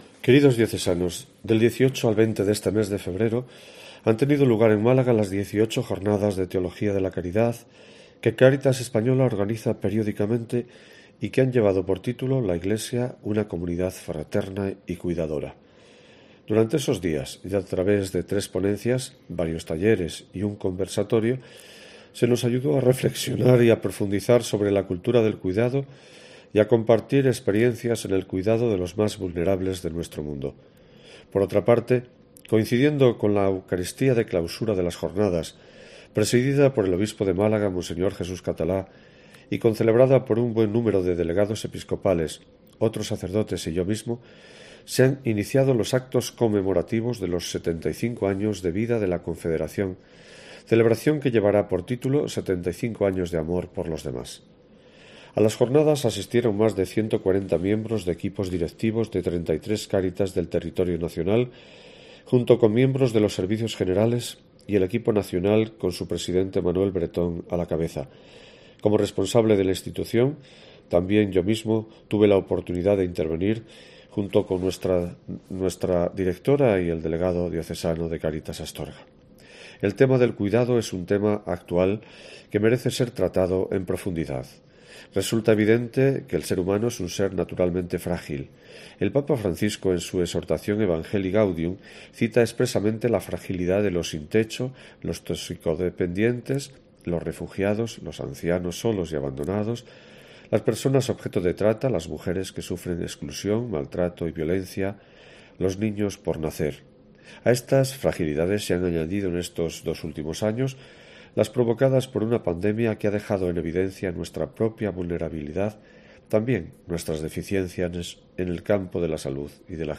Escucha aquí la carta de esta semana del obispo de Astorga